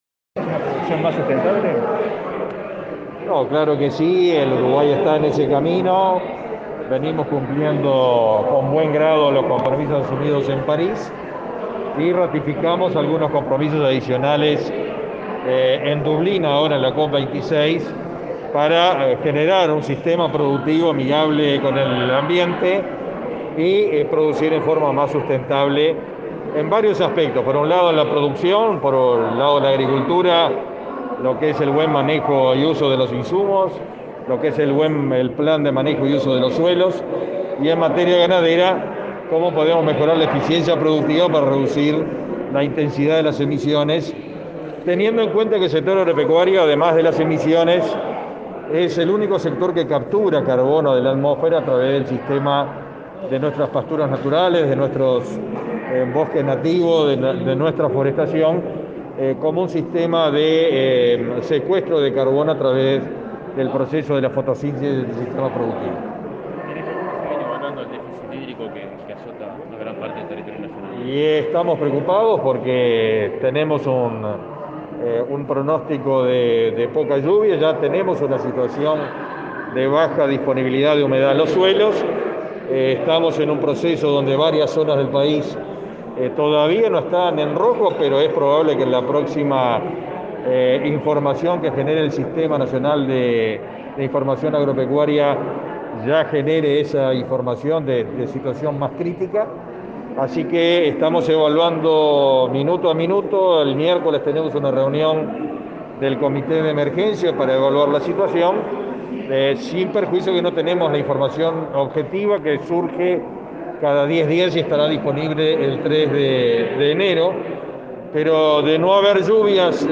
Declaraciones a la prensa del ministro de Ganadería, Fernando Mattos
El ministro de Ganadería, Fernando Mattos, participó este lunes 17, en Torre Ejecutiva, en la presentación de la Estrategia Climática de Largo Plazo